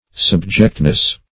Subjectness \Sub"ject*ness\